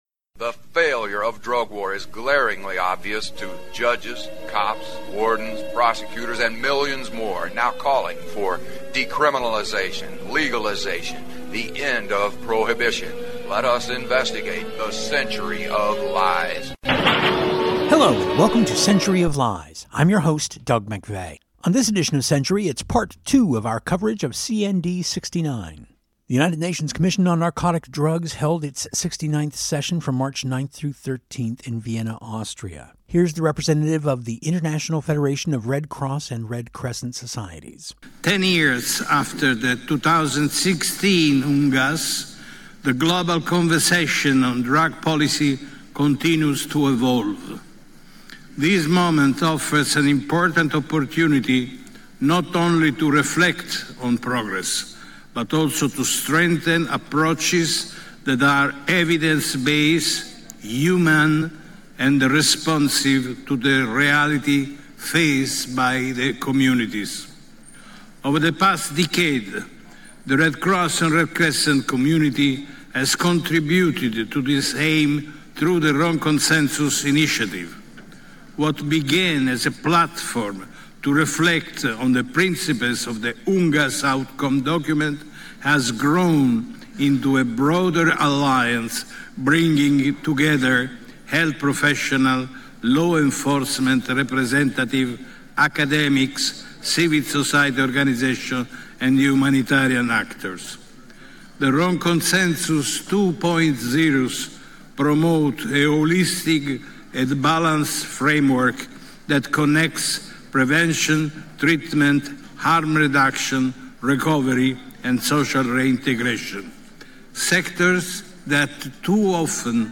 The United Nations Commission on Narcotic Drugs held its 69th session this year from March 9-13 in Vienna, Austria.